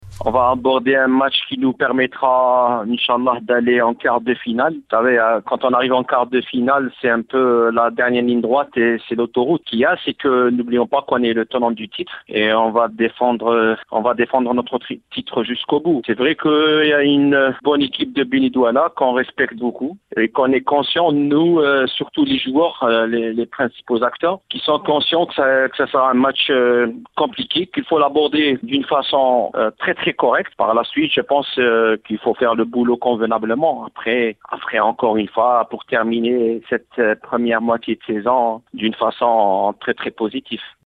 Déclaration